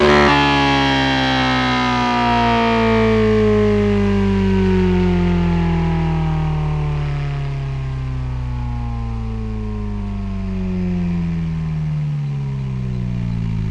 rr3-assets/files/.depot/audio/Vehicles/v8_07/v8_07_Decel.wav
v8_07_Decel.wav